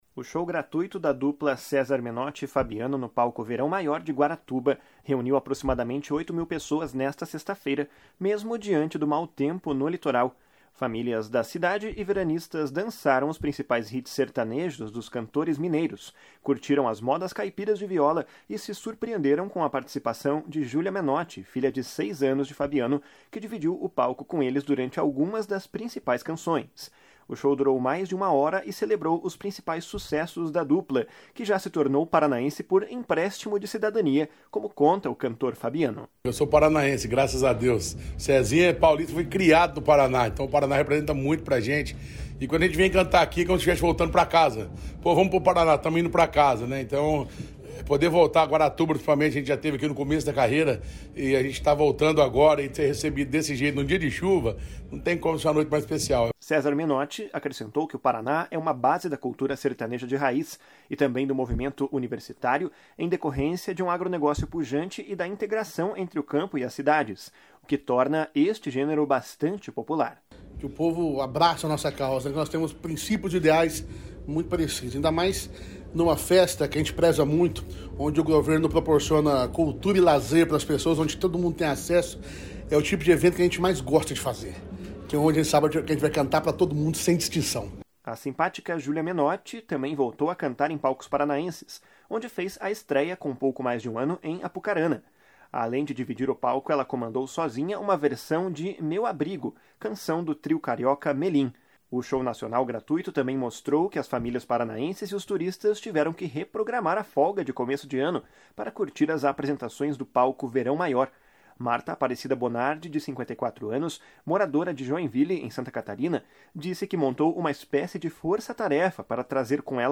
Segundo o prefeito de Guaratuba, Roberto Justus, a programação cultural do Litoral e o Verão Maior ajudaram a impulsionar o movimento em 2020. // SONORA ROBERTO JUSTUS //